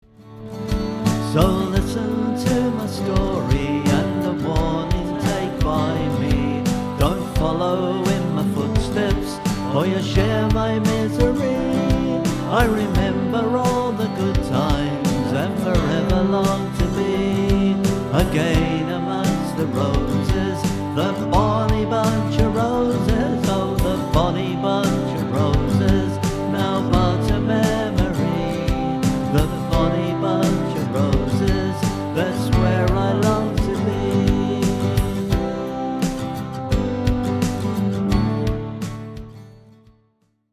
This is a folk song, so don't expect a happy ending.